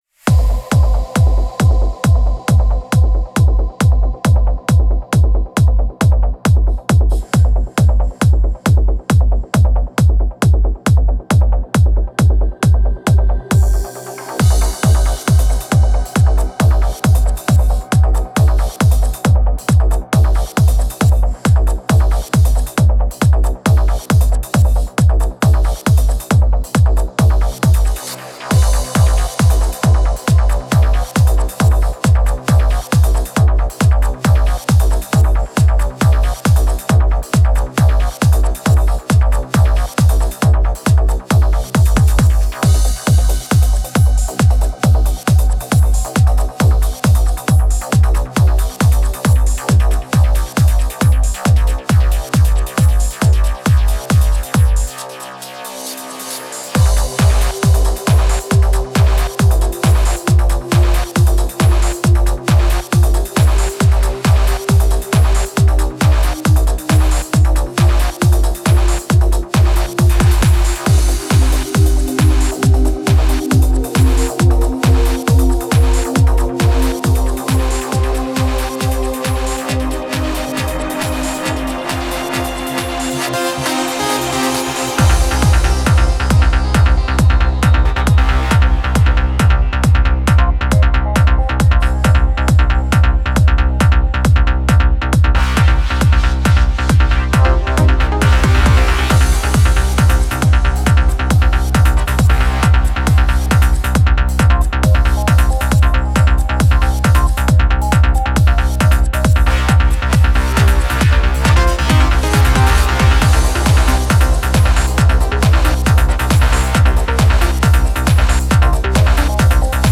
Слышу перед ямой и в яме узнаваемый pad жп-8000.
Асиды потрясные в нём, басы тоже.